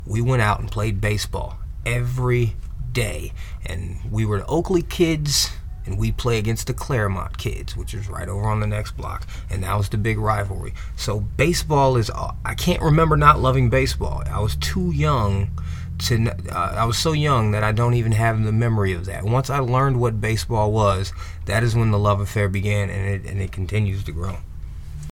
That edited product has yet to be located, but the gift of the original source material is here, in the 10th Inning.
10th-inning-promo.mp3